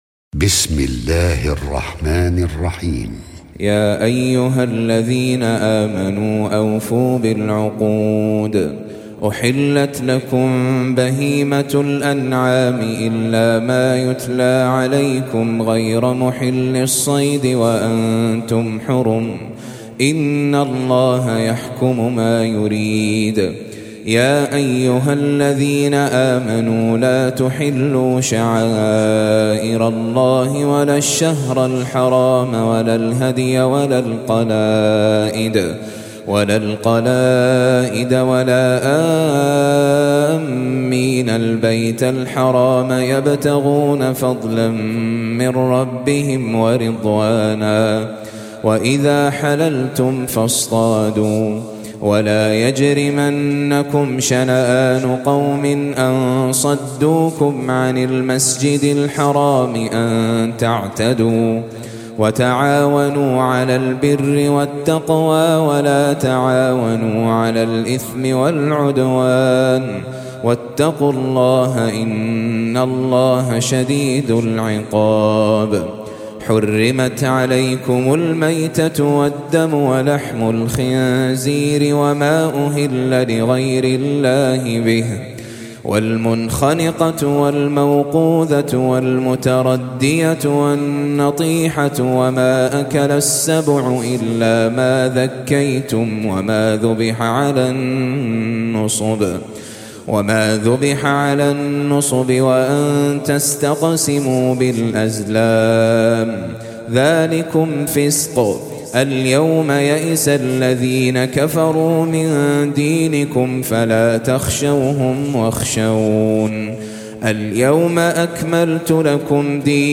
5. Surah Al-M�'idah سورة المائدة Audio Quran Tajweed Recitation
حفص عن عاصم Hafs for Assem
Surah Sequence تتابع السورة Download Surah حمّل السورة Reciting Murattalah Audio for 5.